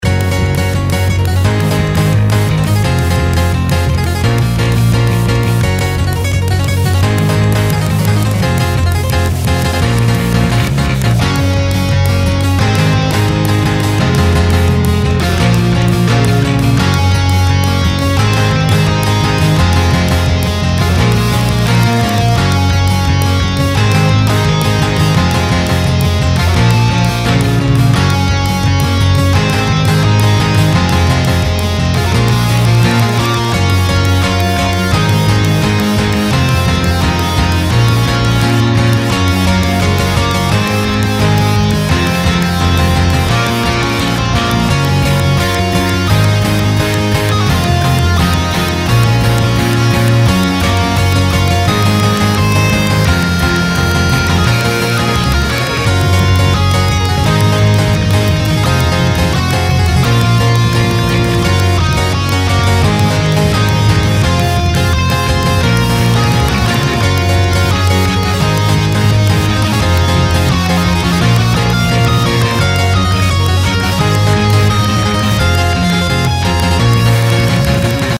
それぞれ１ループの音源です♪
イントロなし